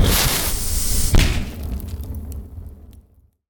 fire-bolt-002-90ft.ogg